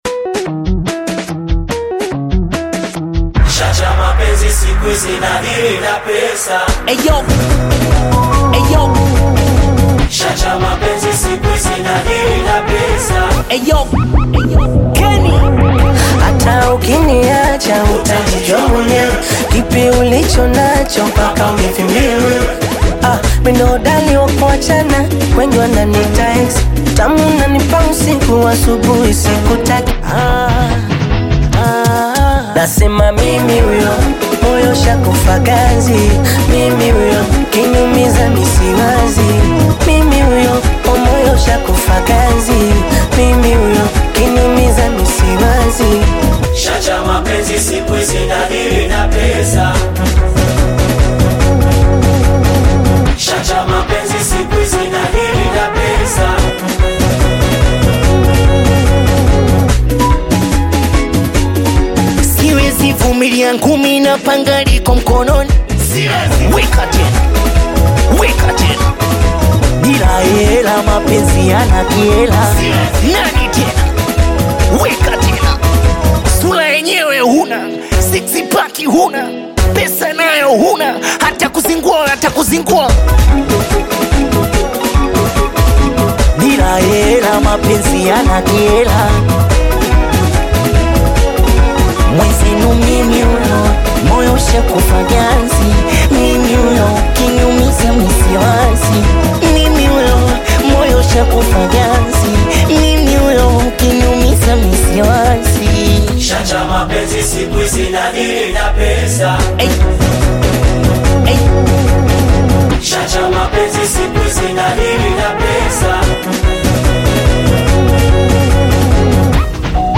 vibrant new Singeli track